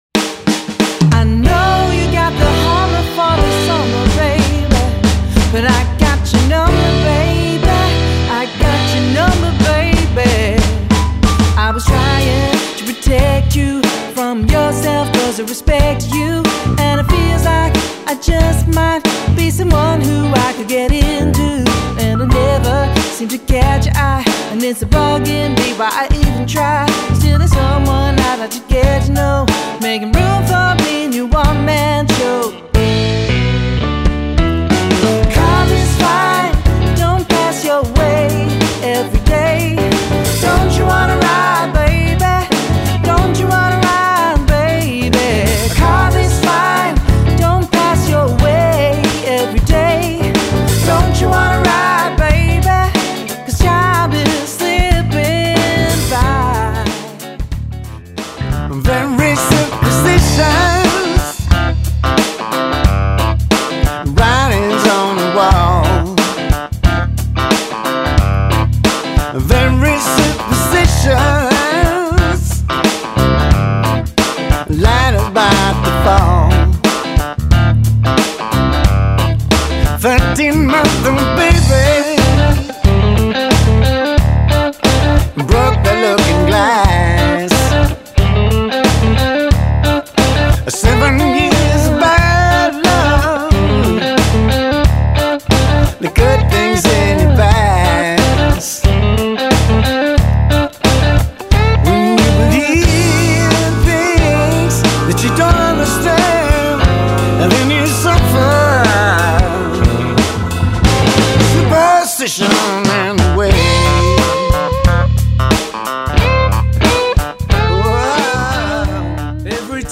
Funk, Rock, Soul and Pop grooves.
Classic Grooves: